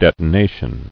[det·o·na·tion]